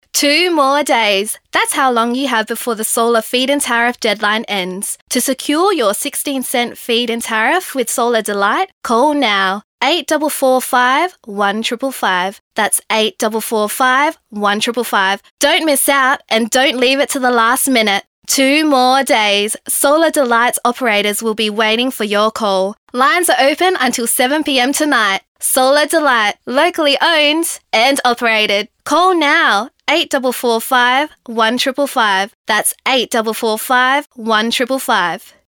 Voiceover